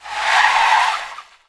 brute_normfriction.wav